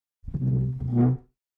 Звуки стула
Слегка подвинул стул